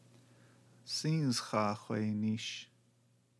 Wondering how to pronounce that?